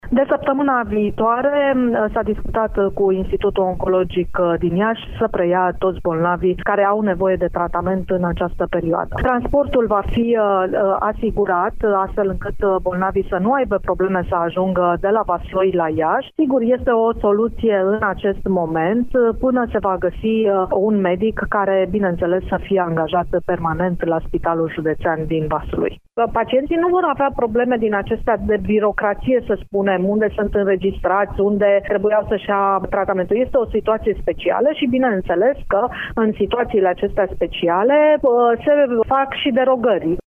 într-o declaraţie acordată în exclusivitate pentru Radio Iaşi: